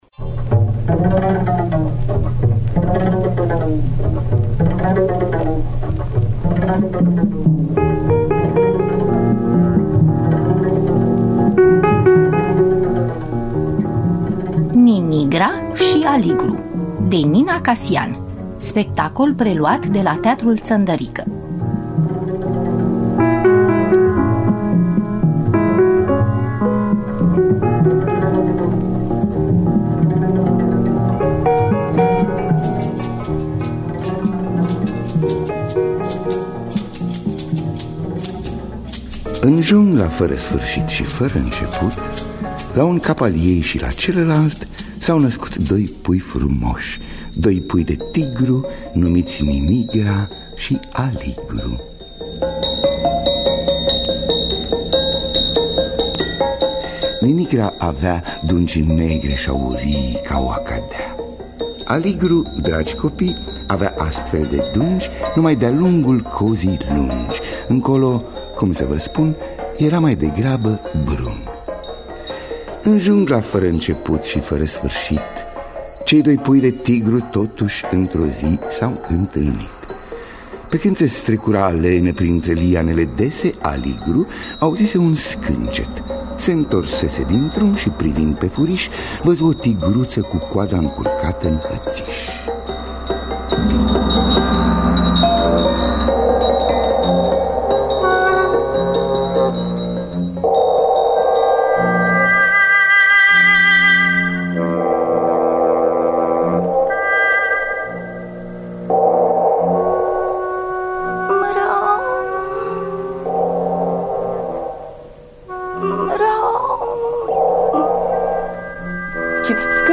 Spectacol preluat de la Teatrul Ţăndărică din Bucureşti.